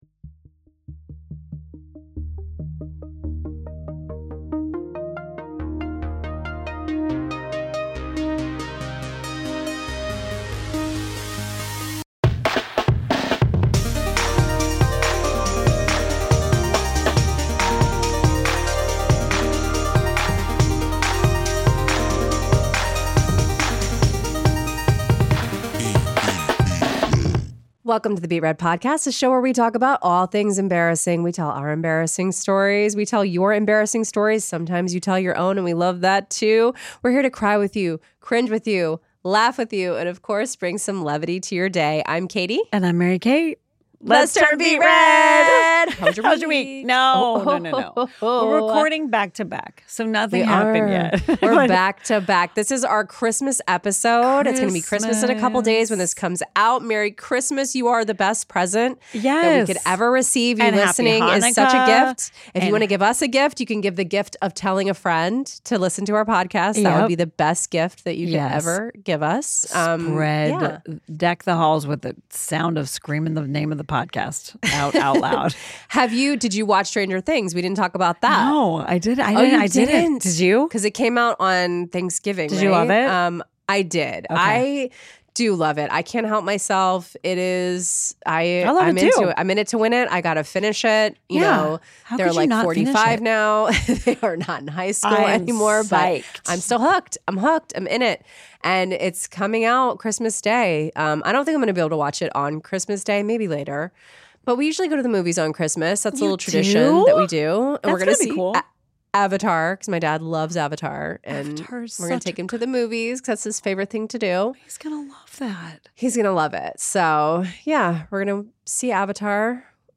Uproarious laughter, glorious distraction and an addiction to hearing more relatable, humiliating tales from the hosts and their listeners.